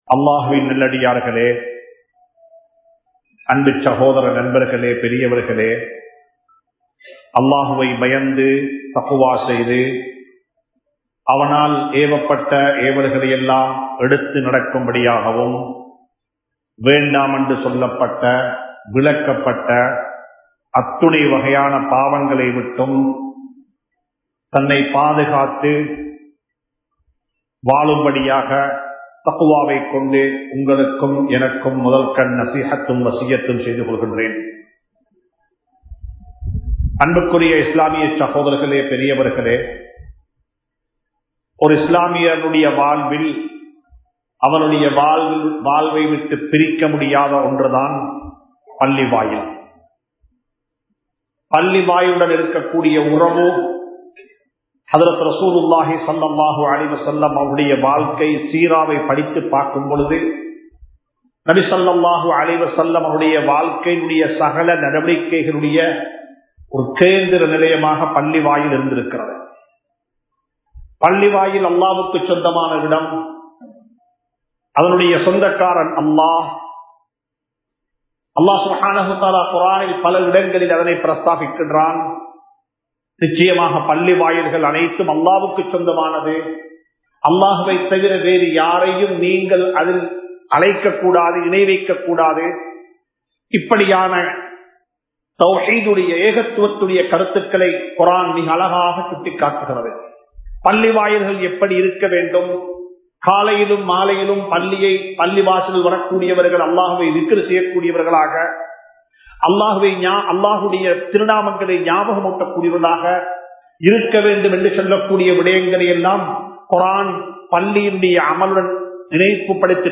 மனிதநேயத்தை உருவாக்கும் மஸ்ஜித் | Audio Bayans | All Ceylon Muslim Youth Community | Addalaichenai